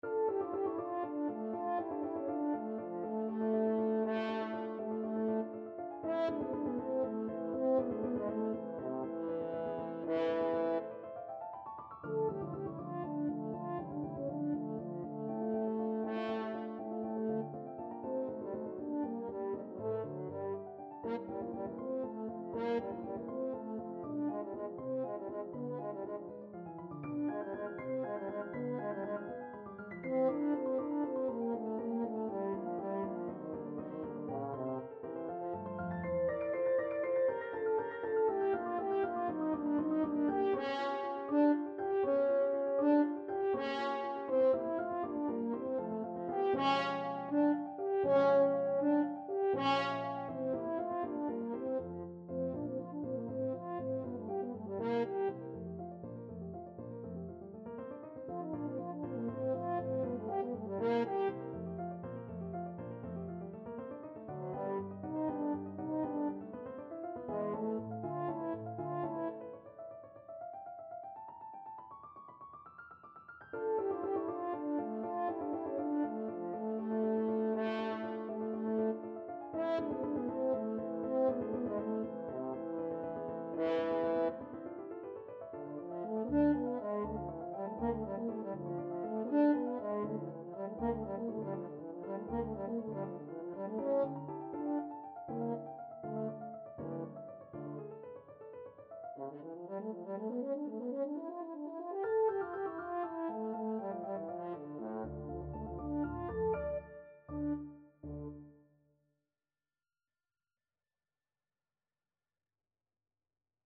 French Horn
D minor (Sounding Pitch) A minor (French Horn in F) (View more D minor Music for French Horn )
. = 80 Allegro Molto Vivace (View more music marked Allegro)
3/8 (View more 3/8 Music)
Classical (View more Classical French Horn Music)